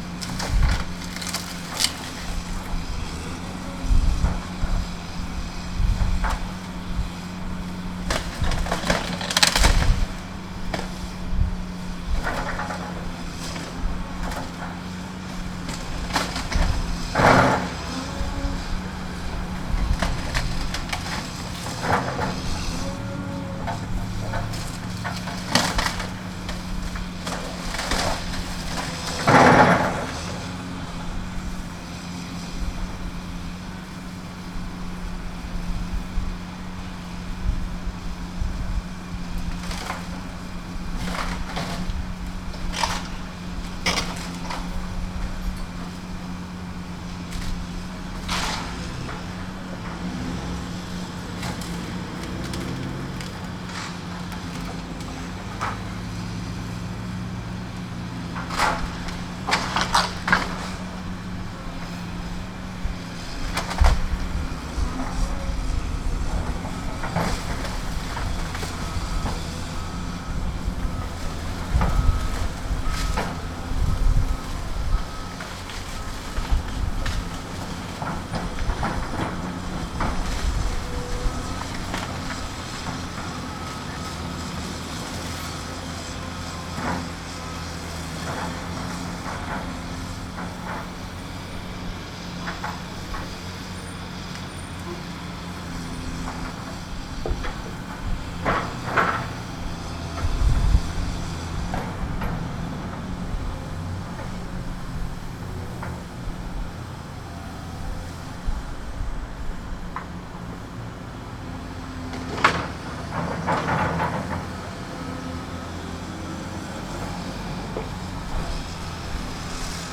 FOLDER 10 - North Burnaby (Recording by Barry Truax with an H2 Zoom Stereo Recorder)
SCHOOL DEMOLITION AT ALBERT & WILLINGDON (August 16 & 18, 2010)
4. Truck drives past at 0:50, boards rustling at 3:24, very faint birdcalls beginning at approx. 3:45, hammering at 3:48.